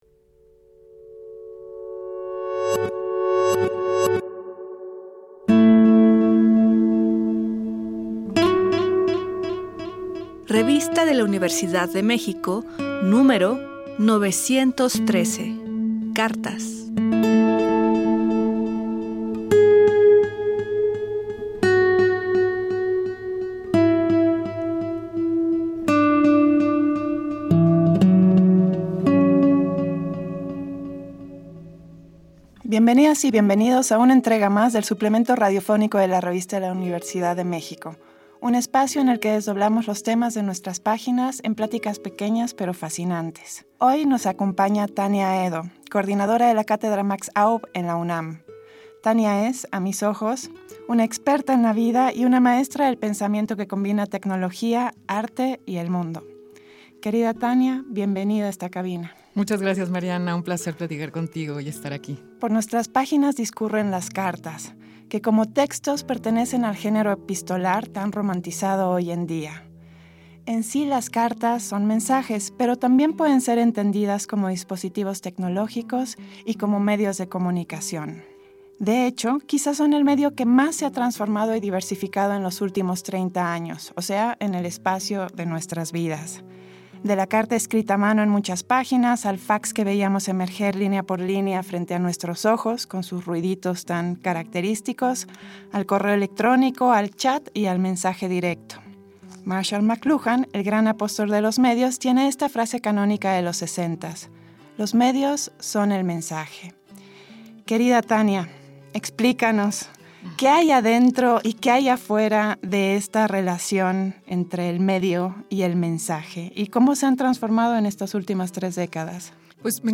Fue transmitido el jueves 10 de octubre de 2024 por el 96.1 FM.